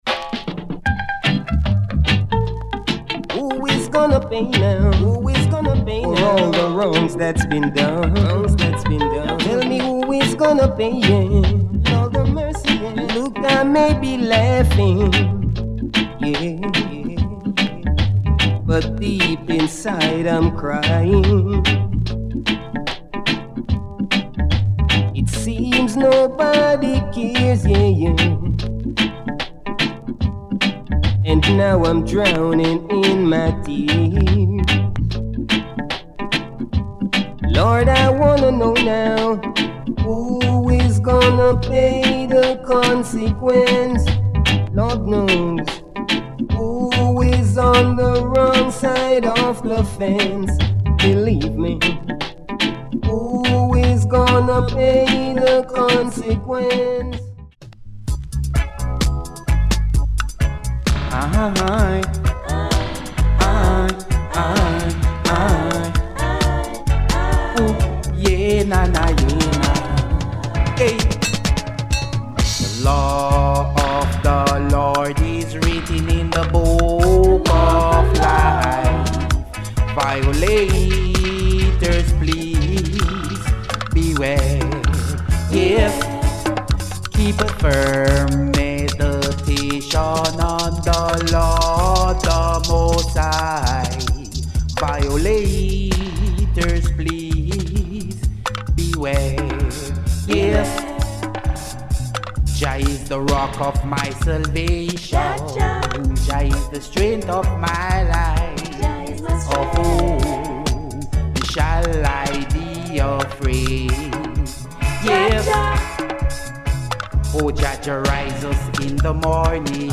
We record our sound files with no EQ is added.